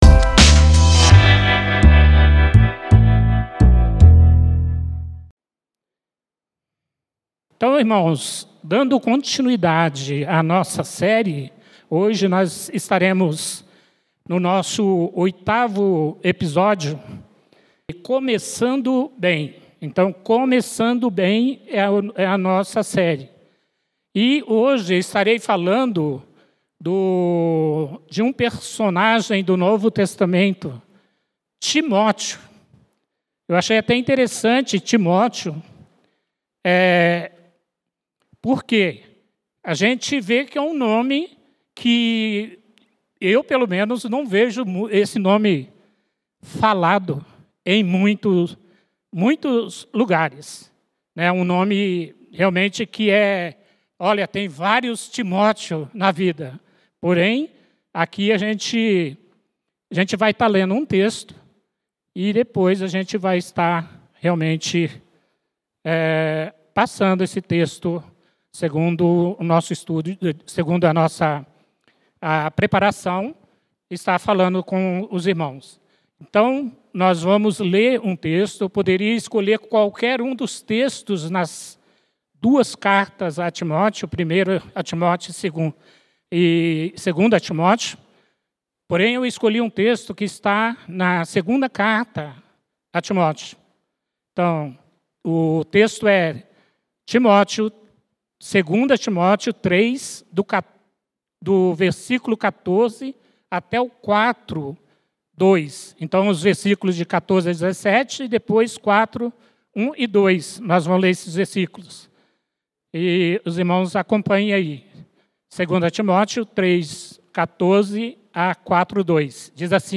Mensagem realizado nos encontros de Reflexão de Oração às Quintas-Feiras 20h.